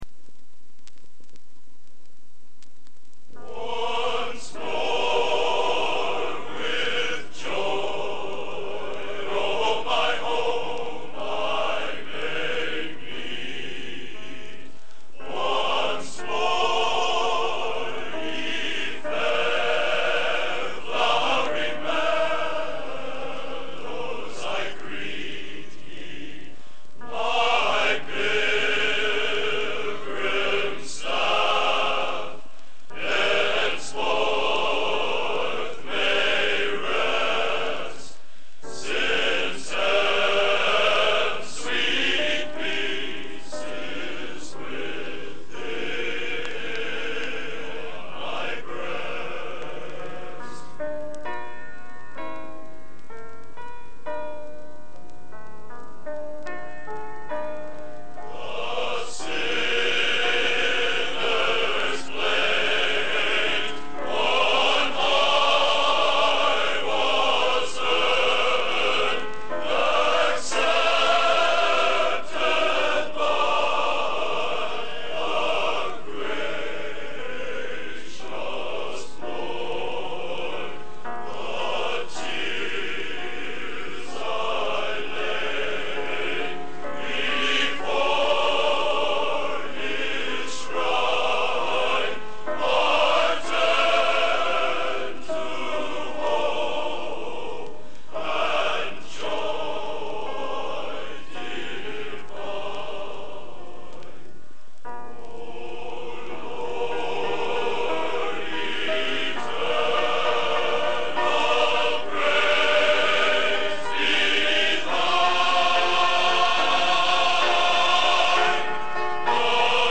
Genre: | Type: Studio Recording